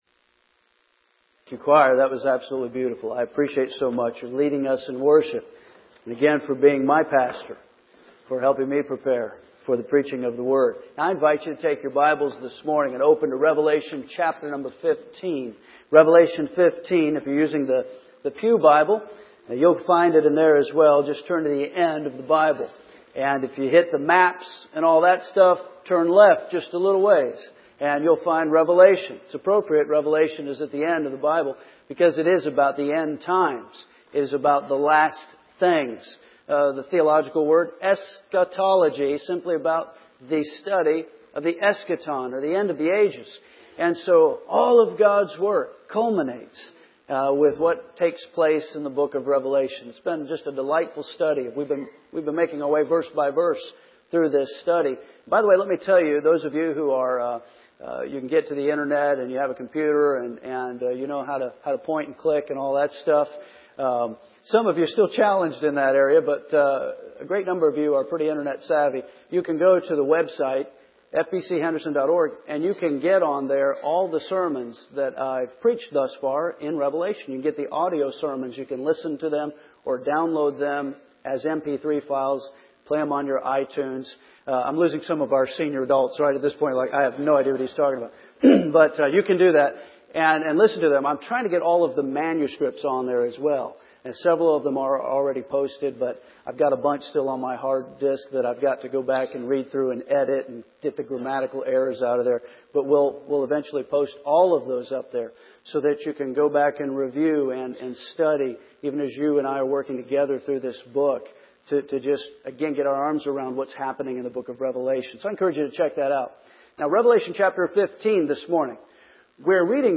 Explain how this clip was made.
First Baptist Church, Henderson KY